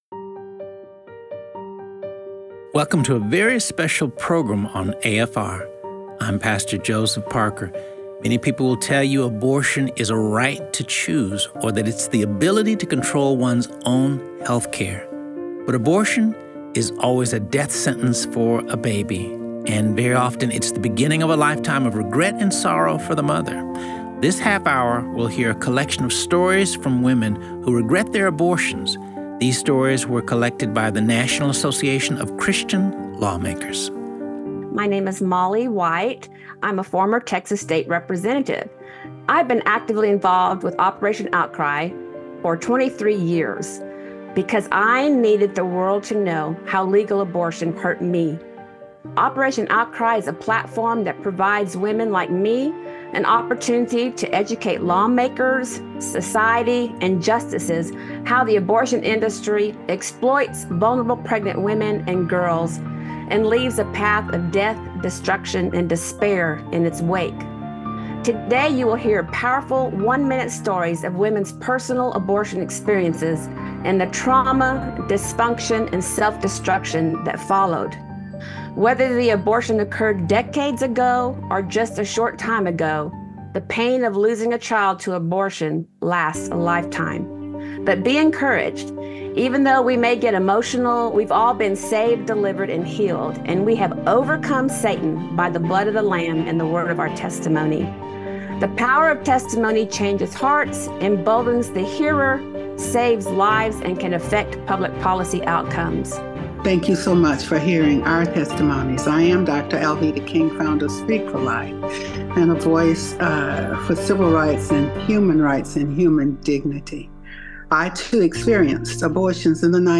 National Association of Christian Lawmakers present this special broadcast from womens personal abortion experiences and how the pain of losing a child to abortion, lasts a lifetime.